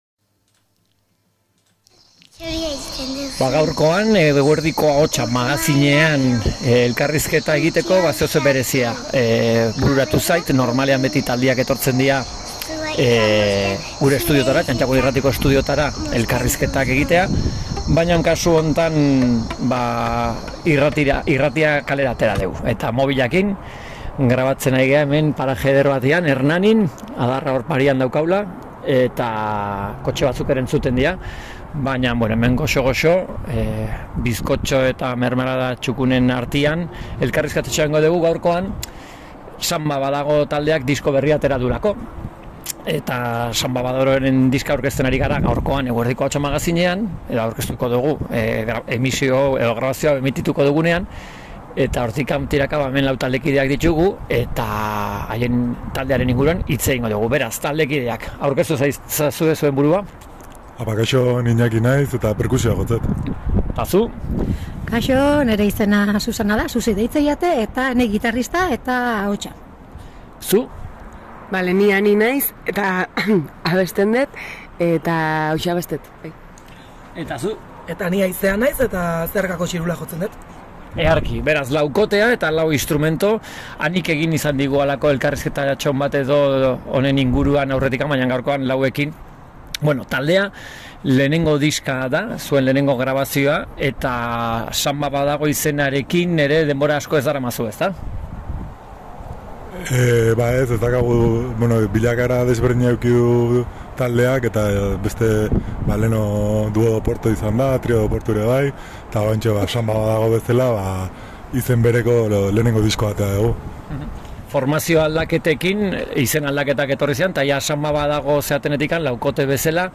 Samba badago taldeak lehenengo diskoa kaleratu dute, eta taldea elkarrizketatu dugu Eguerdiko Ahotsa magazinean.
Elkarrizketak